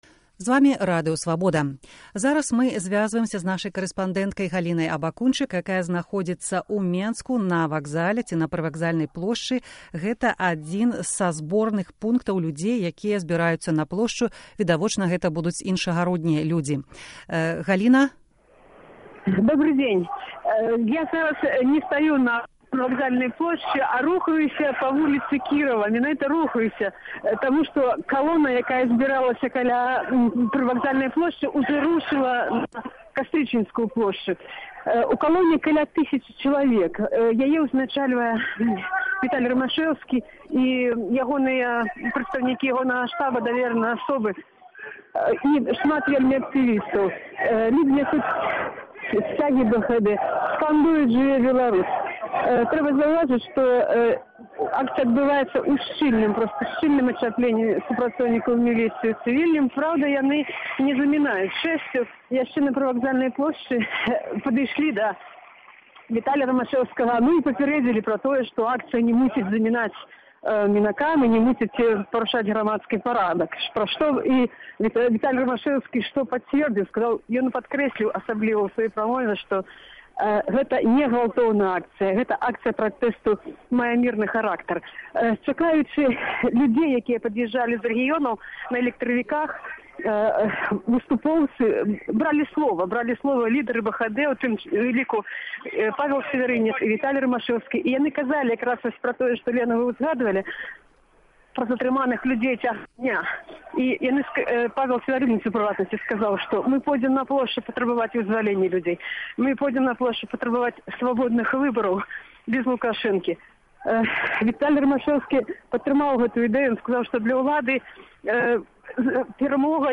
Рэпатаж з калёны, якая рушыць у бок Кастрычніцкай плошчы